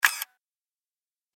دانلود آهنگ دوربین 4 از افکت صوتی اشیاء
جلوه های صوتی
دانلود صدای دوربین 4 از ساعد نیوز با لینک مستقیم و کیفیت بالا